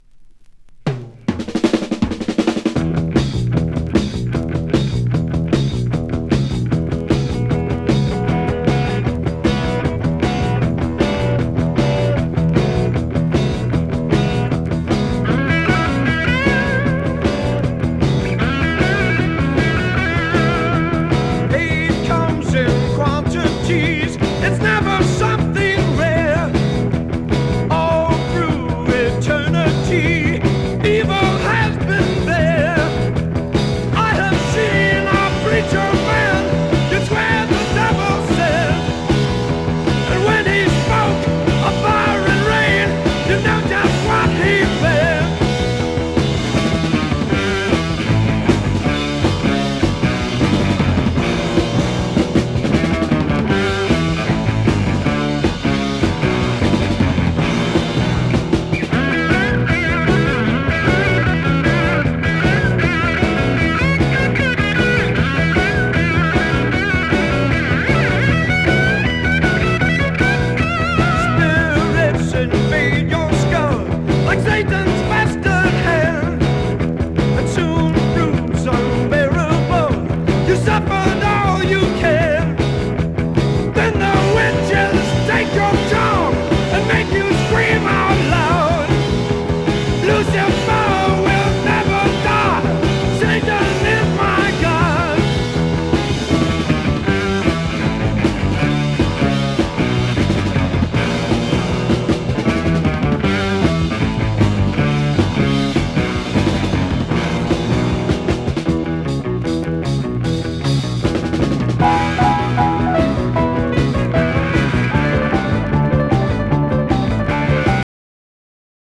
少しスワンプ臭さも漂ってるハードロックバンドで好きな人多そうだ。